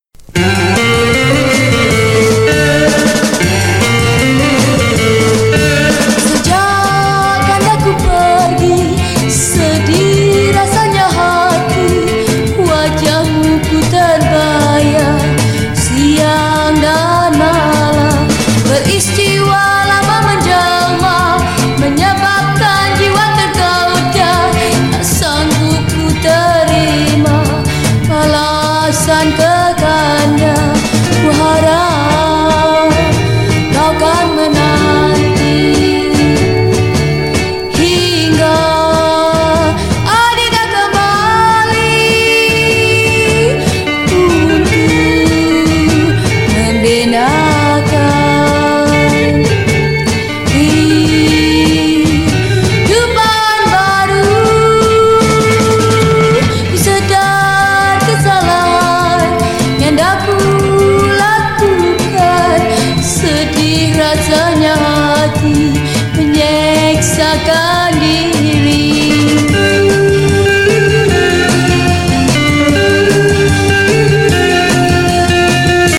Genre: Rock, Pop
Style: Pop Rock, Cantopop, Hokkien Pop, Mandopop